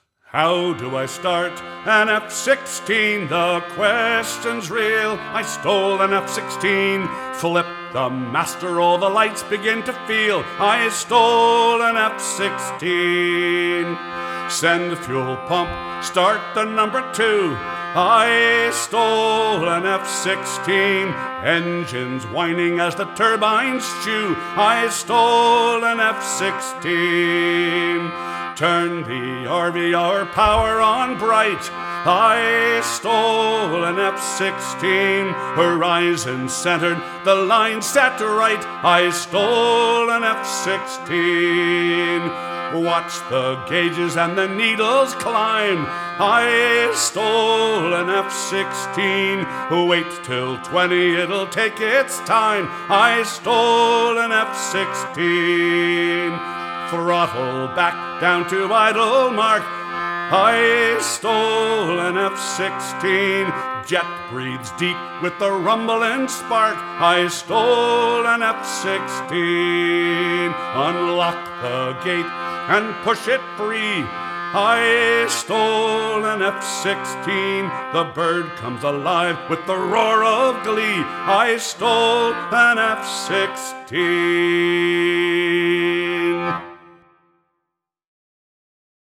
AI generated song